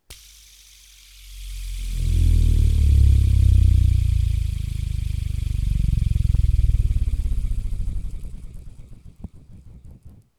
• spinning hitting air close up - microphone.wav
Recorded in a small cabinet with a Tascam Dr 40. Can also help with windy bass sounds.
spinning_hitting_ait_close_up_-_microphone__eBo.wav